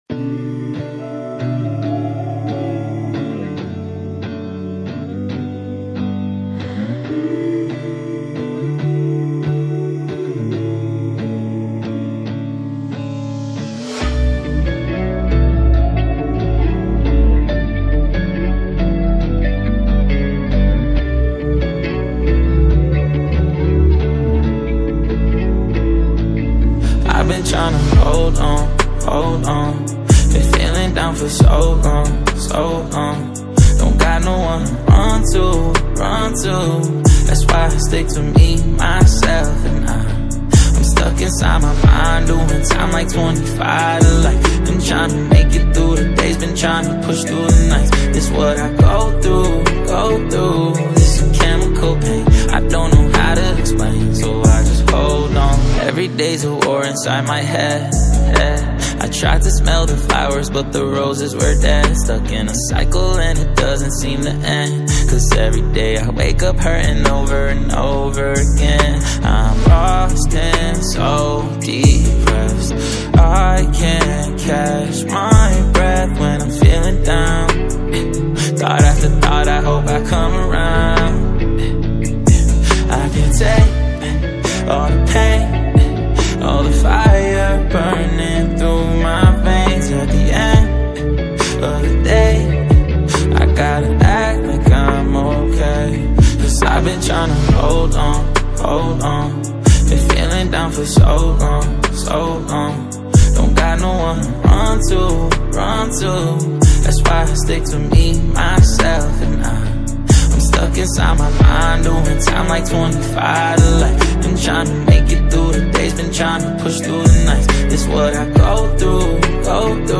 American Gospel MusicForeign MusicGospel MusicVideo
A Heartfelt Worship Anthem.